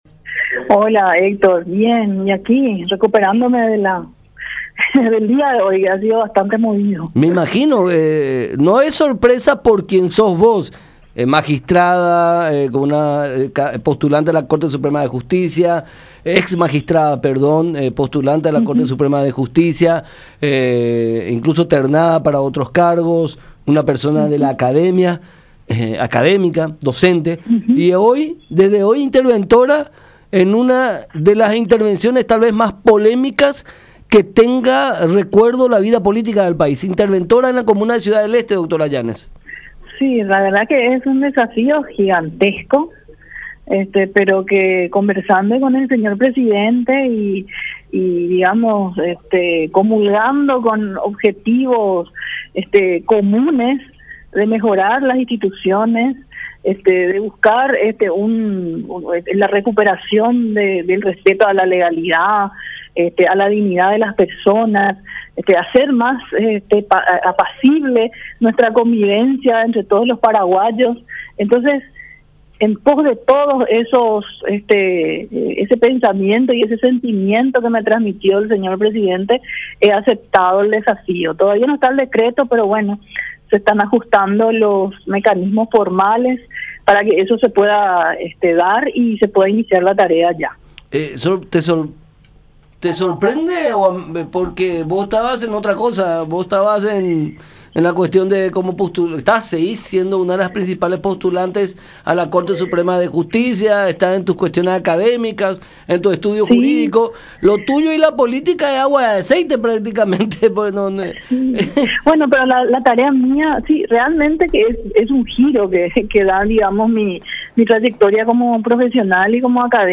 “Conversamos y comulgamos en deseos comunes de la recuperación de la legalidad, transparencia y confianza en las instituciones”, destacó Llanes en comunicación con La Unión, subrayando que el mandatario le ha dado plena libertad de acción para elegir a sus ayudantes.